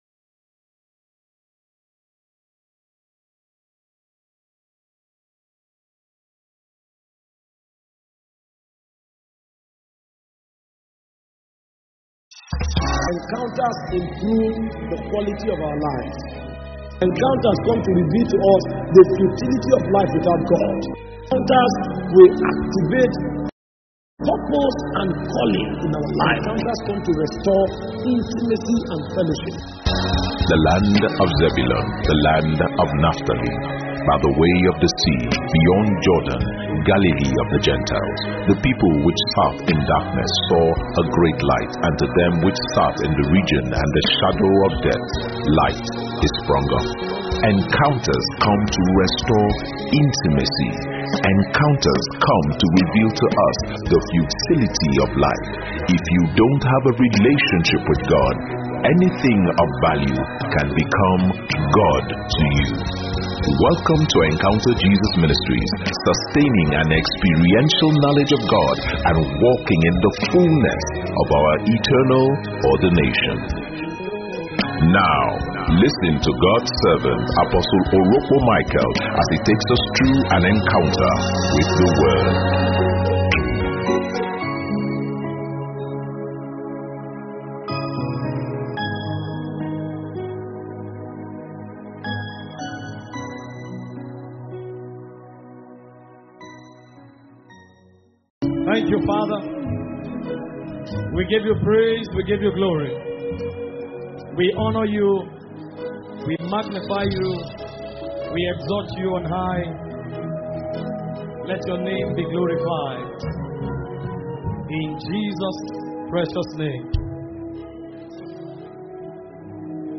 [Sermon]